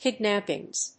/ˈkɪˌdnæpɪŋz(米国英語)/